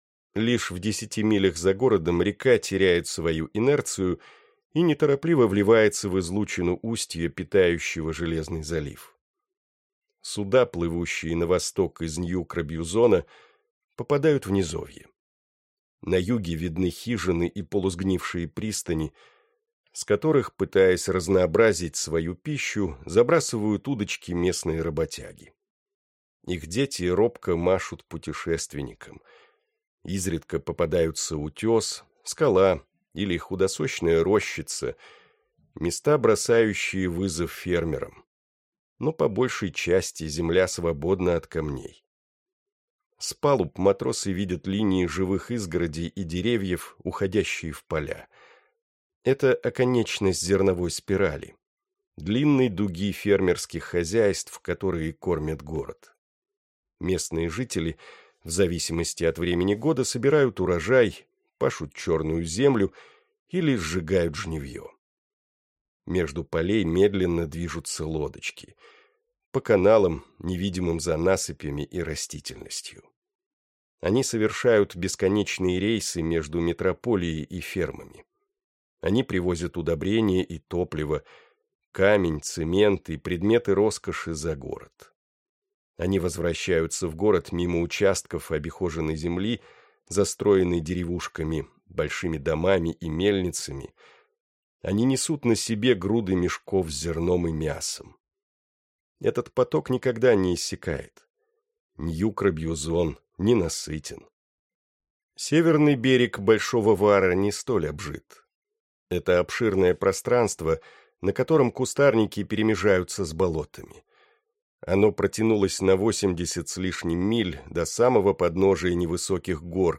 Аудиокнига Шрам | Библиотека аудиокниг